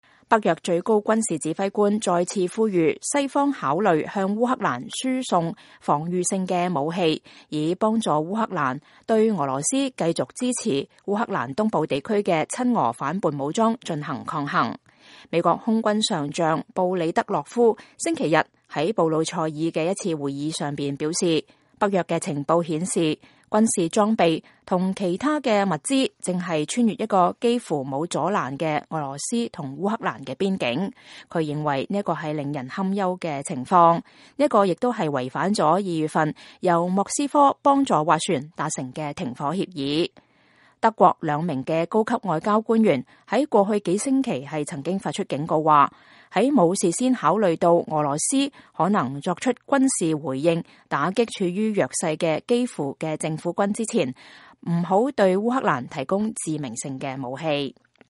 北約歐州盟軍最高司令部司令員布里德洛夫在基輔舉行的記者會上講話（2014年11月）